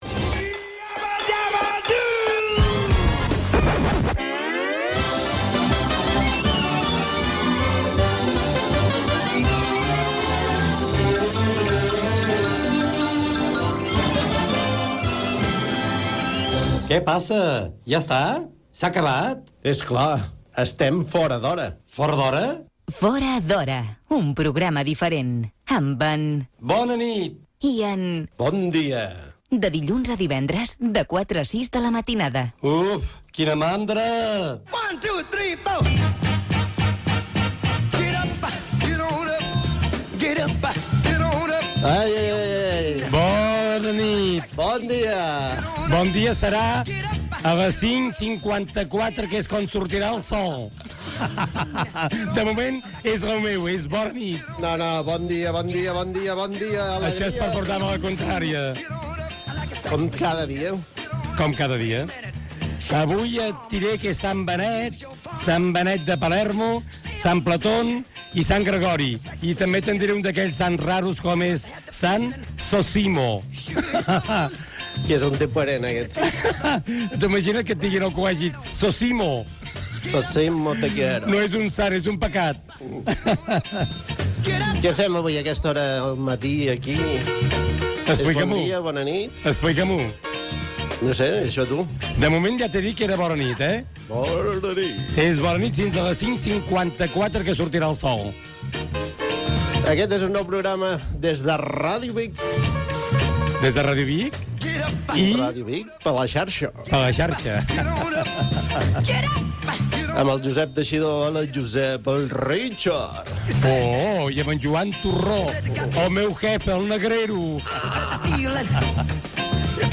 Careta, inici del programa, hora de la sortida del sol, santoral, equip, sumari